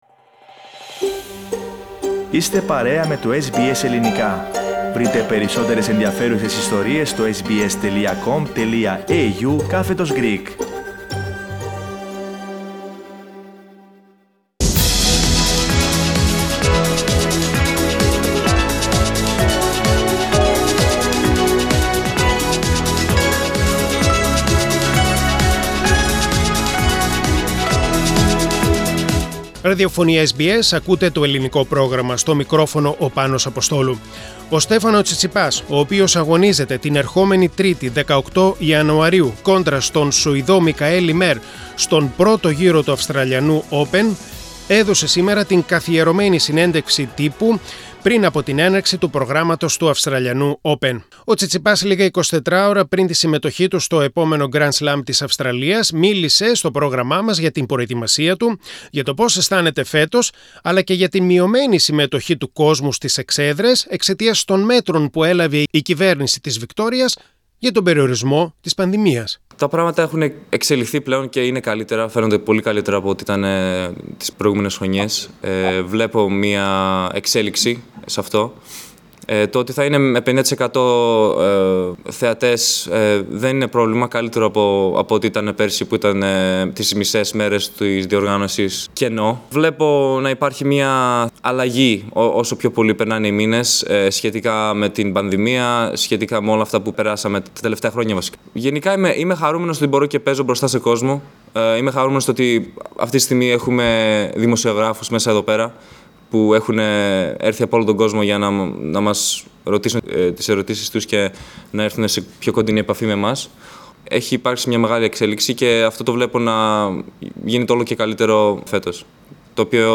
Συνέντευξη Τύπου παραχώρησε σήμερα στο μεσημέρι (Σάββατο) ο Στέφανος Τσιτσιπάς λίγα 24ωρα πριν ξεκινήσει η αγωνιστική δράση για το φετινό Γκραντ Σλαμ της Μελβούρνης.